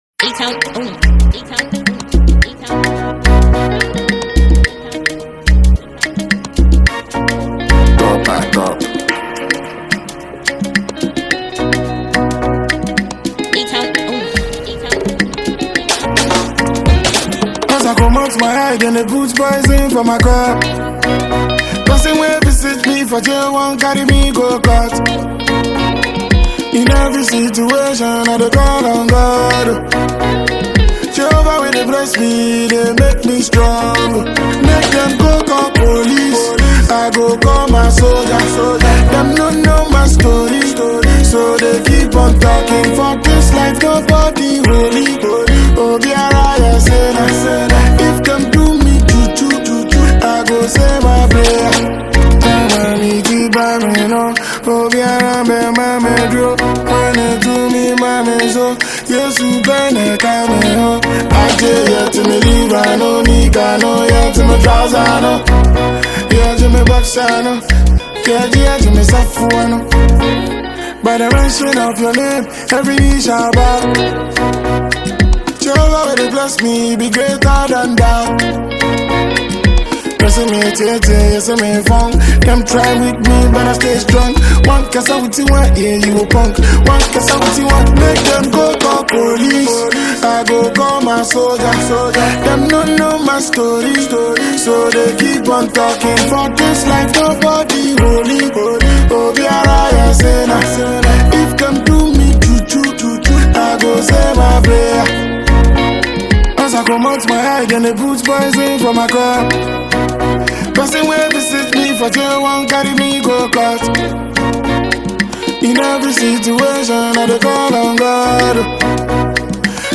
Noteworthy Ghanaian rapper and songwriter